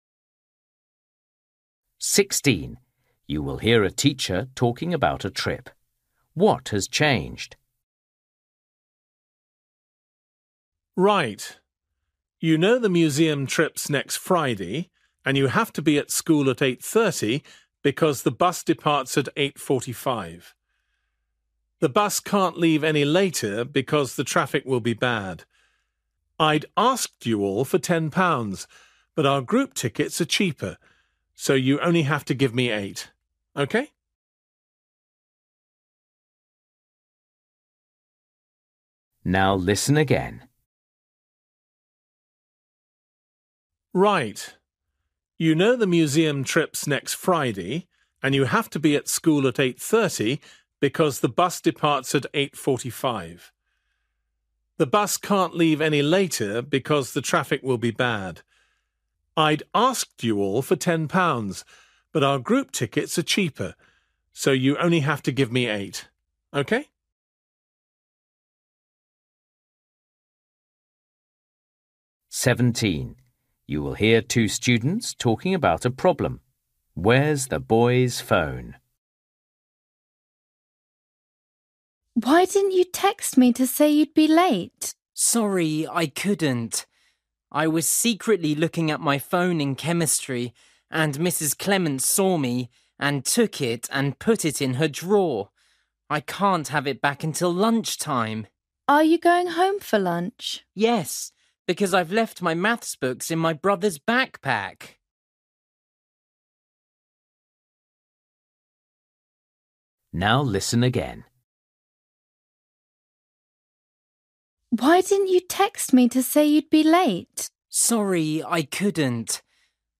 Listening: everyday short conversations
16   You will hear a teacher talking about a trip. What has changed?
17   You will hear two students talking about a problem. Where’s the boy’s phone?
19   You will hear a girl talking about playing tennis. How does she feel after playing?